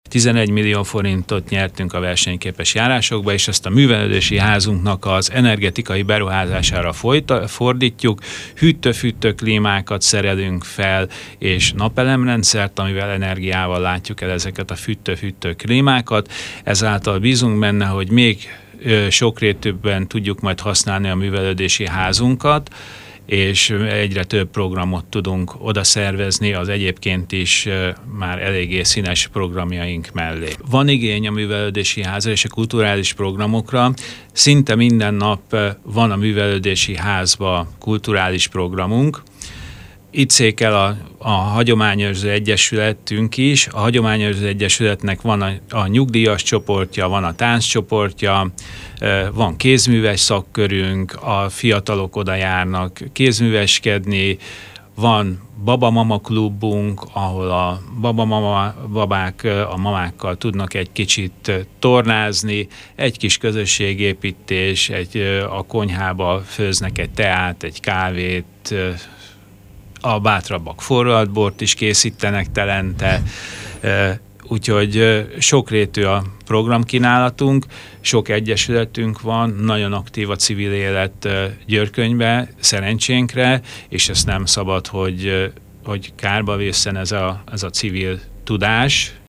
Györköny A Művelődési ház energetikai korszerűsítésére használja fel a Versenyképes Járások Programban elnyert 11 millió forintot, ezt Schweigert György polgármester mondta el rádiónknak. A polgármester arra is kitért, hogy nagy igény van a kulturális intézményre, mert számos aktív egyesület működik a településen.
schweigert-hirek.mp3